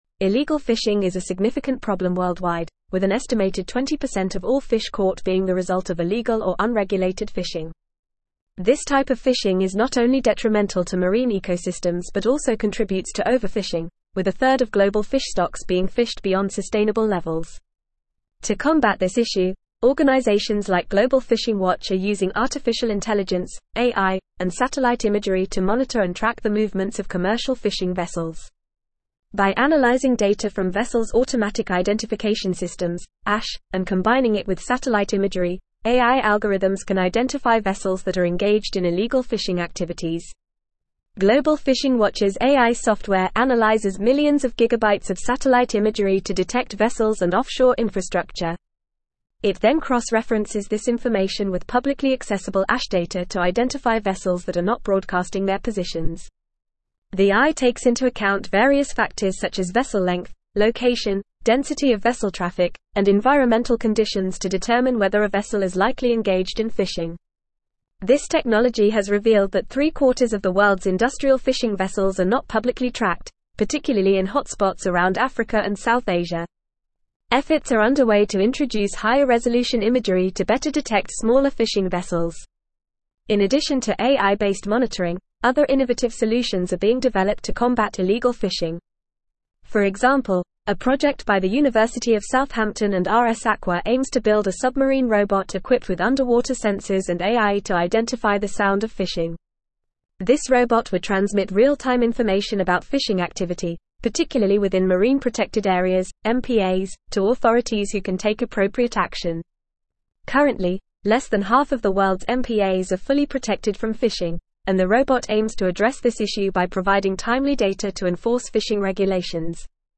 Fast
English-Newsroom-Advanced-FAST-Reading-Using-AI-and-Robotics-to-Combat-Illegal-Fishing.mp3